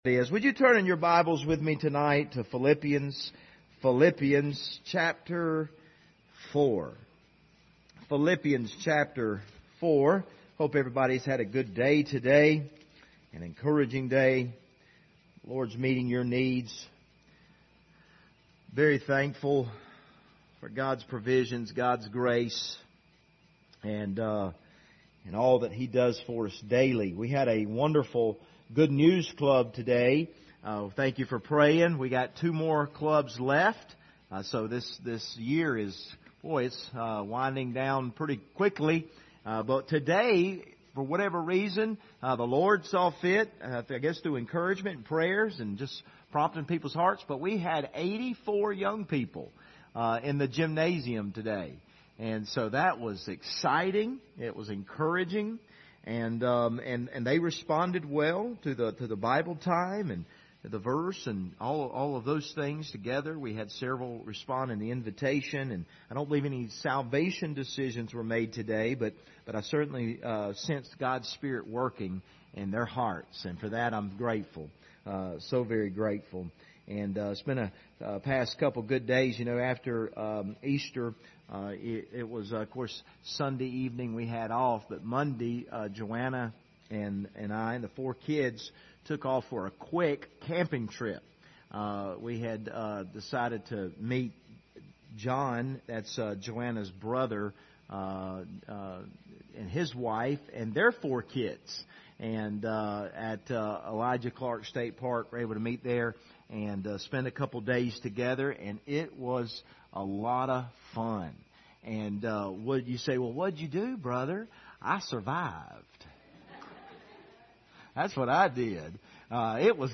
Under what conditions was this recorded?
Passage: Philippians 4:8 Service Type: Wednesday Evening